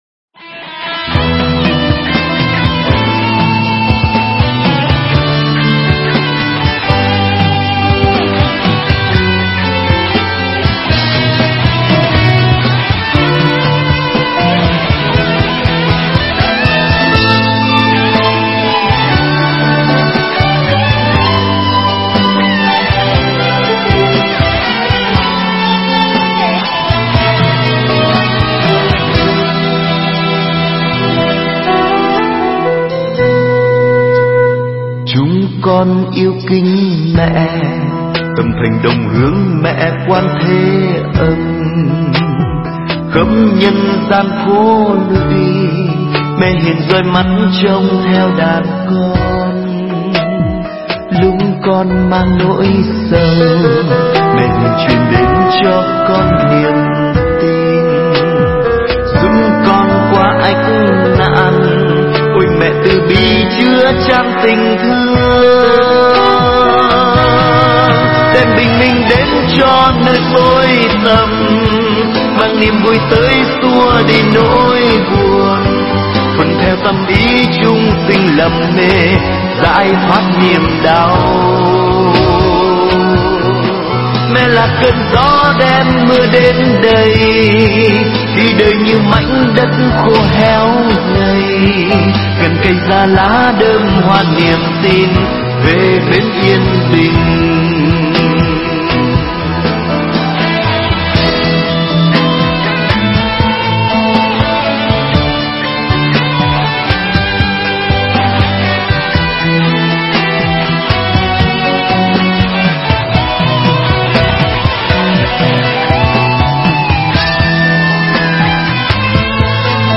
Nghe Mp3 thuyết pháp Hình Tượng Của Đức Quán Thế Âm Bồ Tát